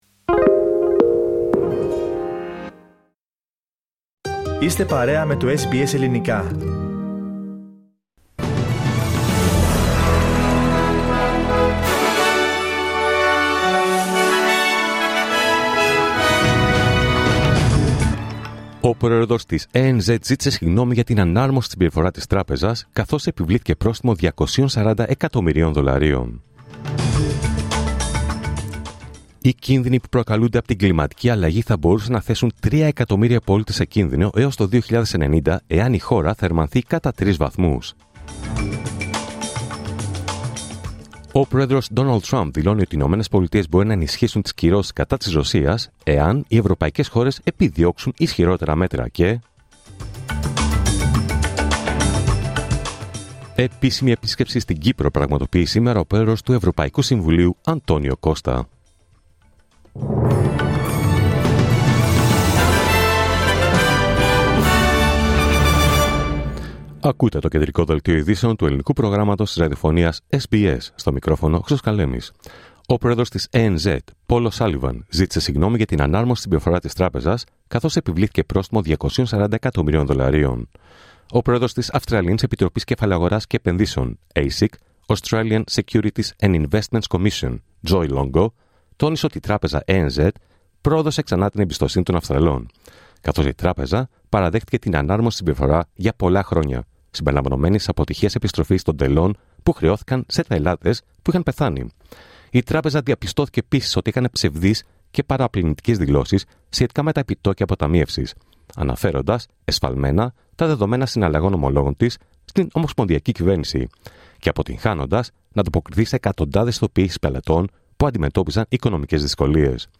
Δελτίο Ειδήσεων Δευτέρα 15 Σεπτεμβρίου 2025